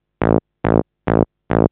SNTHBASS009_DANCE_140_A_SC3.wav